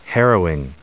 1584_harrowing.ogg